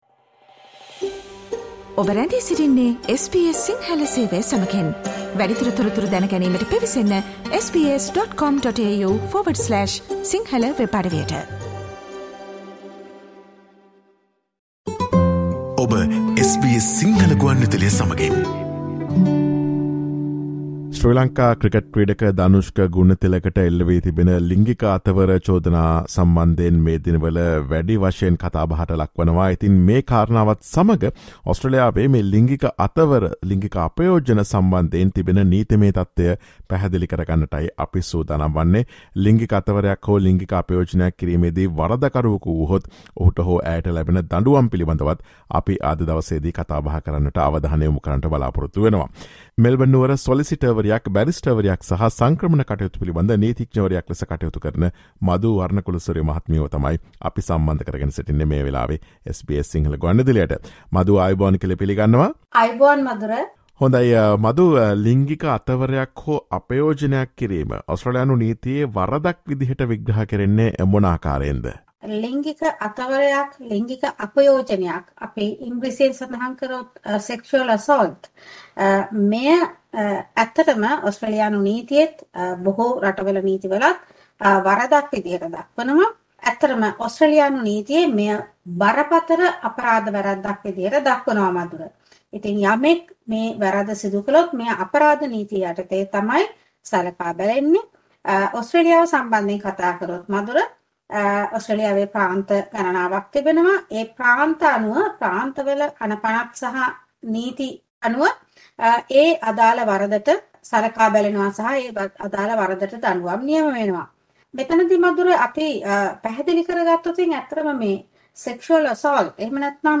Listen to SBS Sinhala Radio's discussion of how sexual assault is interpreted as an offense under Australian law and the penalties for a sexual assault conviction.